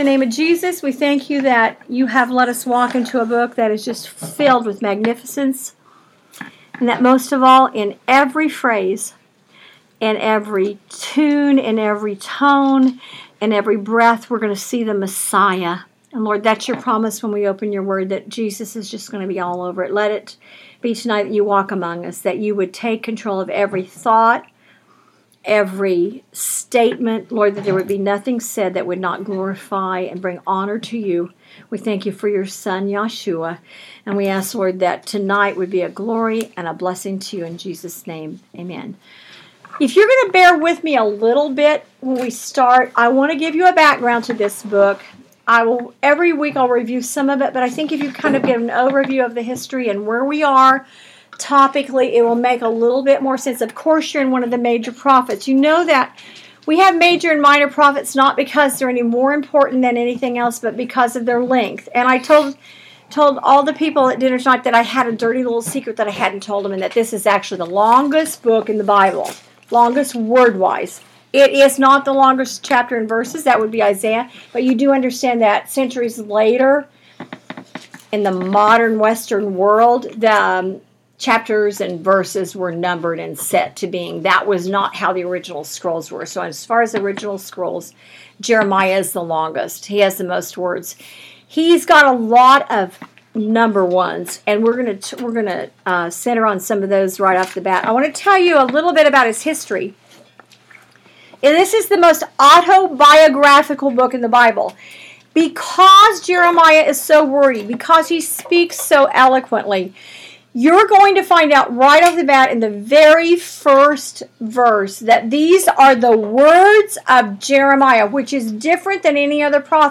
Bible Study Audio